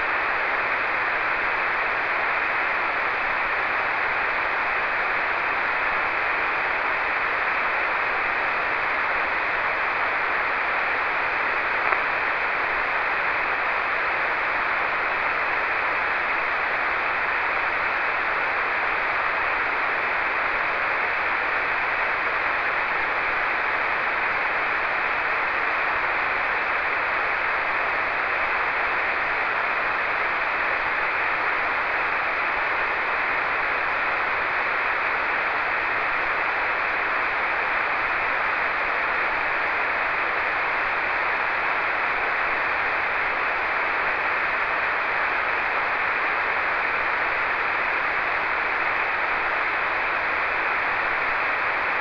Prove di ascolto WSJT - 8,9 aprile 2011
Antenna: 16JXX, Apparato: FT897
nessun preamplificatore
N.B.: Solo nel primo file si può ascoltare un flebile "suono"... per tutti gli altri solo utilizzando WSJT si ha evidenza dei segnali ricevuti.